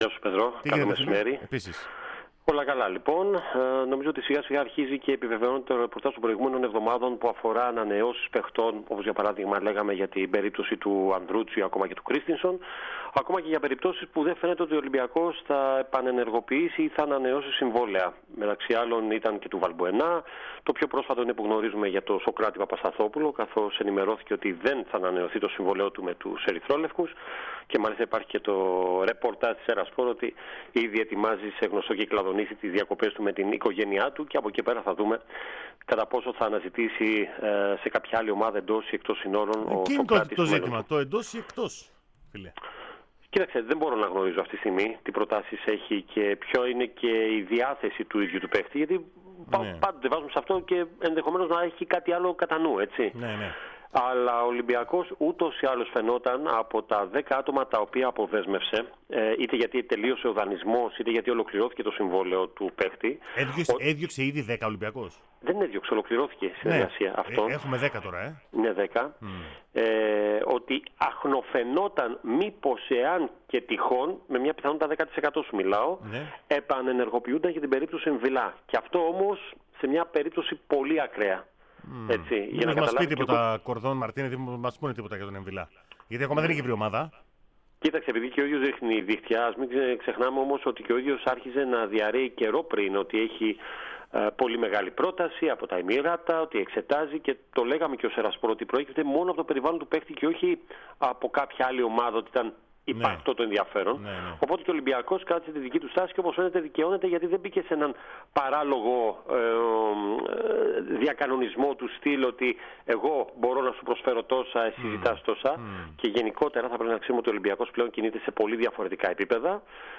στον “αέρα” της ΕΡΑ ΣΠΟΡ